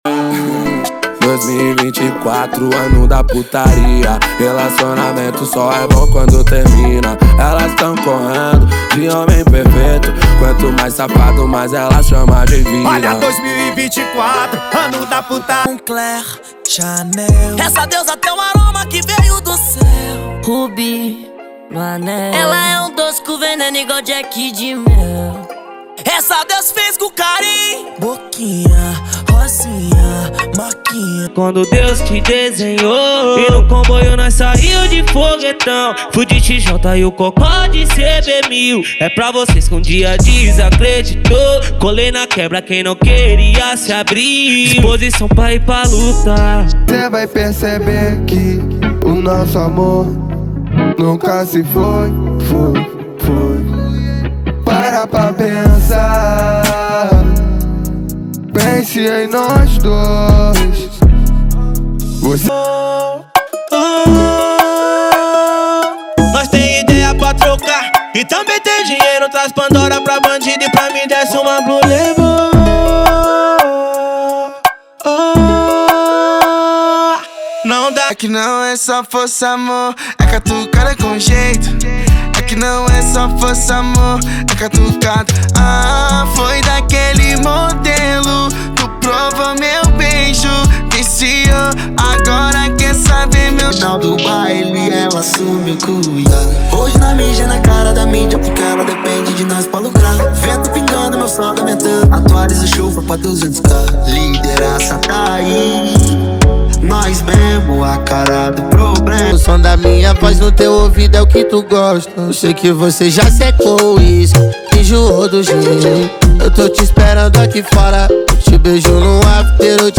• Rap, Trap Nacional e Funk Ostentação = 50 Músicas
• Sem Vinhetas
• Em Alta Qualidade